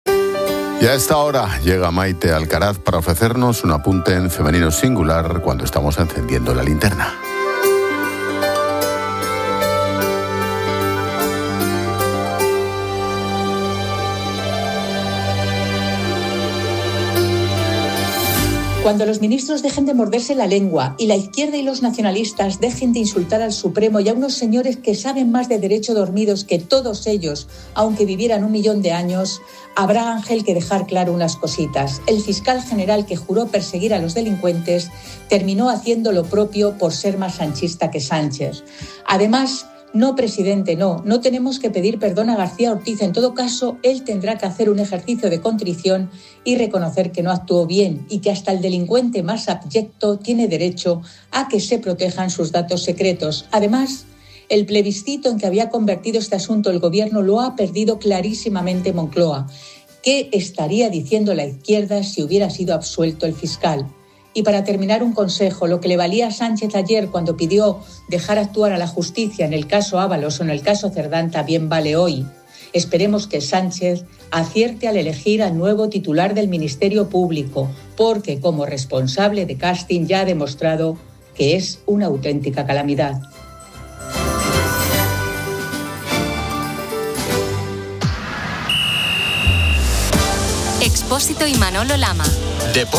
Análisis de la condena al fiscal general